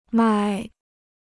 迈 (mài): to take a step; to stride.